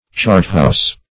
Chargehouse \Charge"house`\